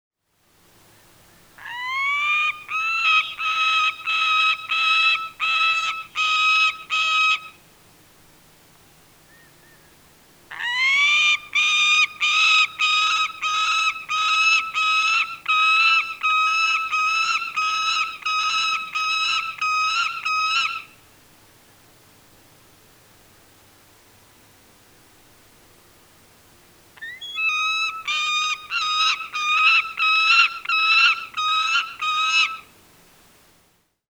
Звуки сокола
Звуки сапсана в момент крика птица семейства соколиных